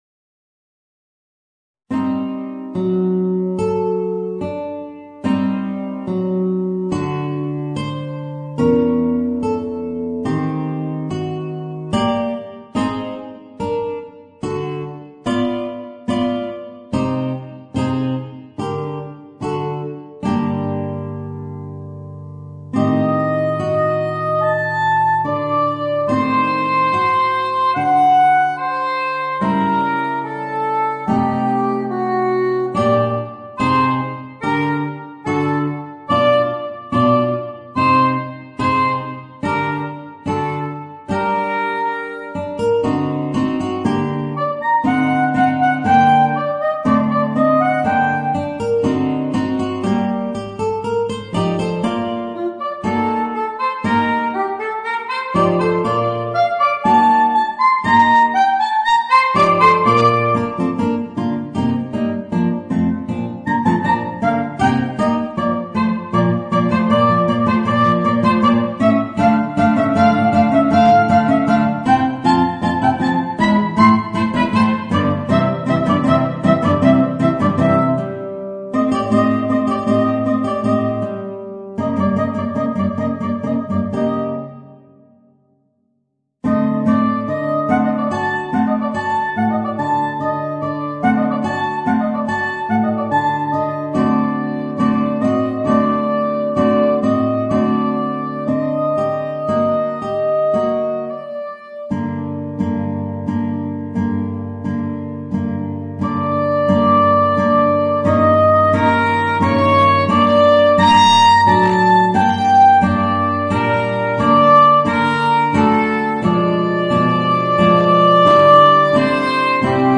Voicing: Soprano Saxophone and Guitar